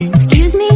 Darmowe dzwonki - kategoria SMS
Dźwięk przepraszam w języku angielskim z miłym dla ucha akcentem.